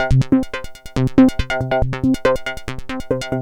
tx_synth_140_hardsync_C1.wav